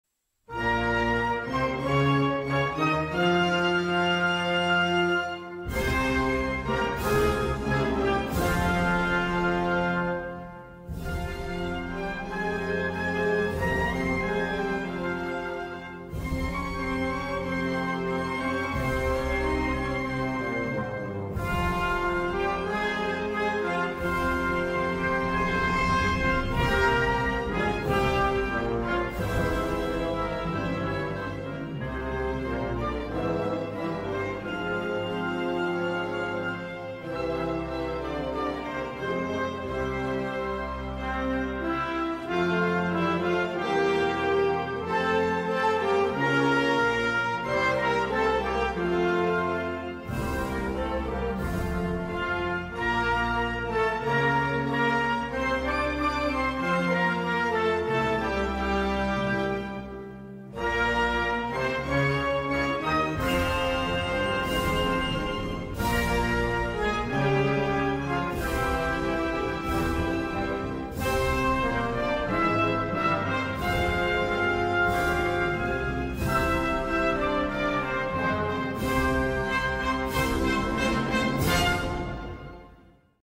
Оркестровая аранжировка